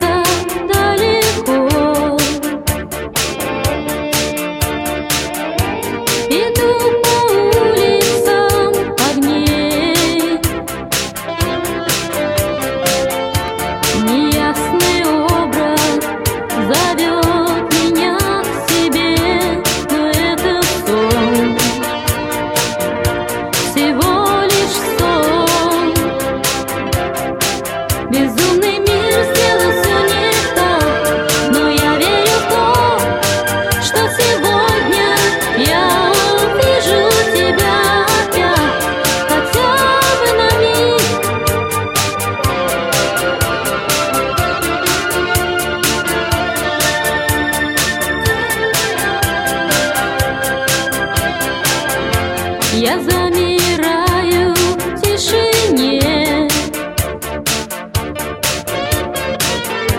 Pop
диско-группы